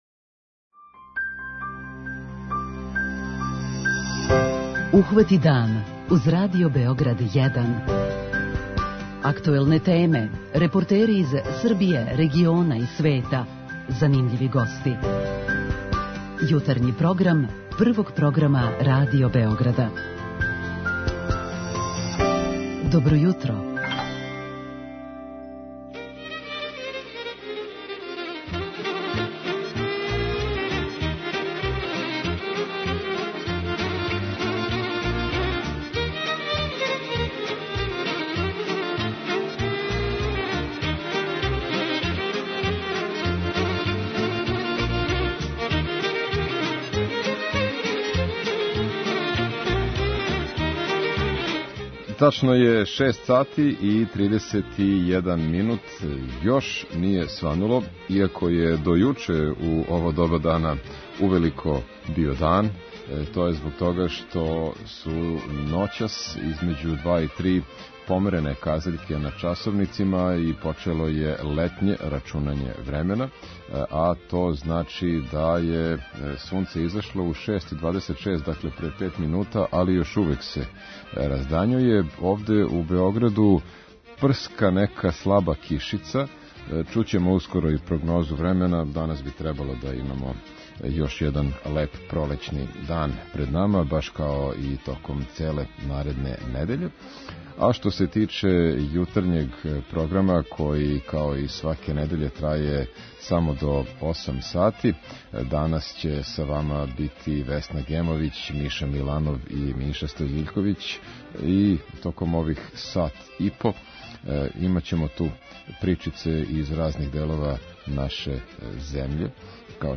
Један део нашег јутарњег програма зато ћемо посветити њима. преузми : 16.19 MB Ухвати дан Autor: Група аутора Јутарњи програм Радио Београда 1!